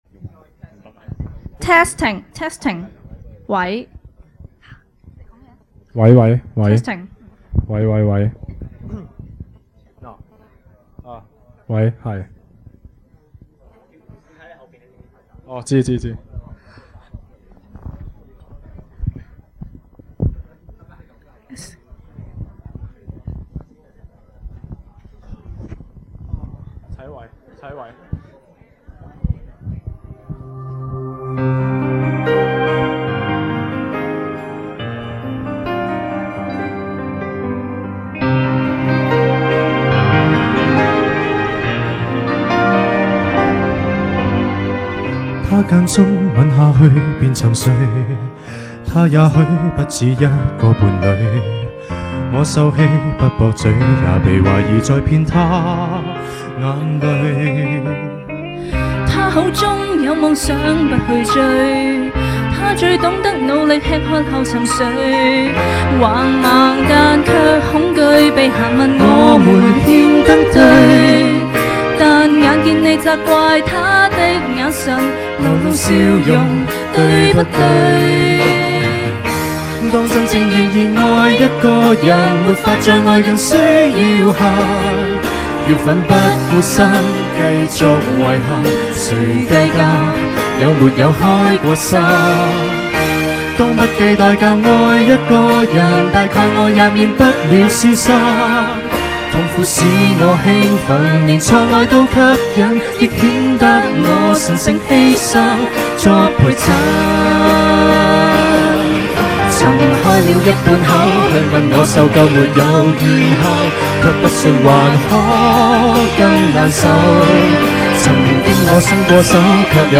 On December 4th 2004, members of various classes gathered at Emerald City Chinese Restaurant to find out who is the King/Queen of Karaoke.